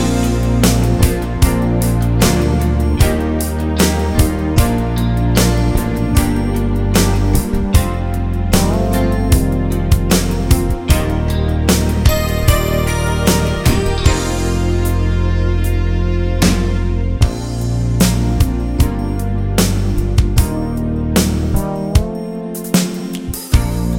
Live Version Pop (1960s) 3:33 Buy £1.50